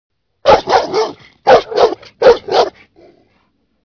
دانلود صدای پارس سگ بزرگ از ساعد نیوز با لینک مستقیم و کیفیت بالا
جلوه های صوتی